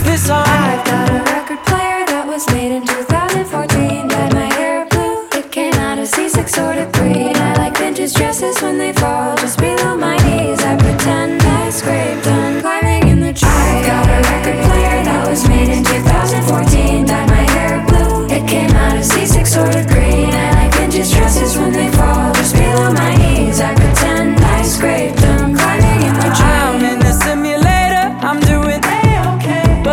Жанр: Альтернатива
Alternative